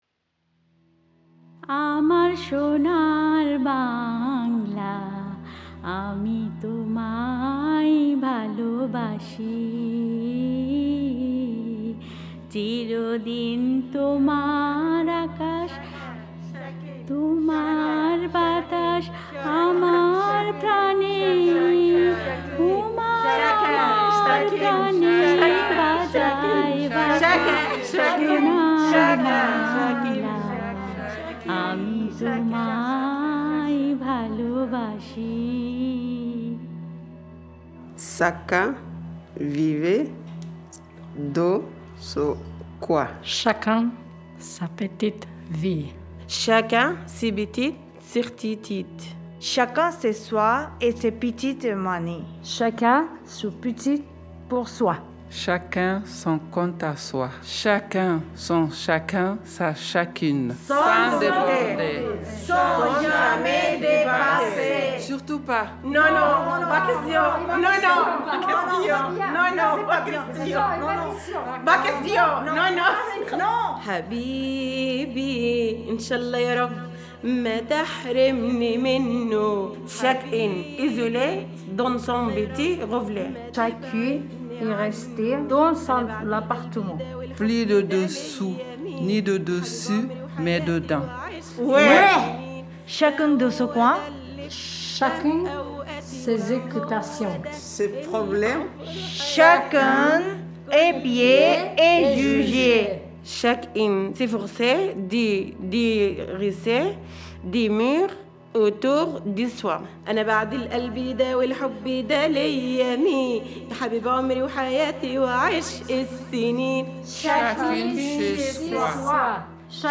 mise en voix du texte, ATELIER DE FRANÇAIS Groupe Vie quotidienne, MAISON DE QUARTIER DES TEMPS DURABLES, LIMEIL-BRÉVANNES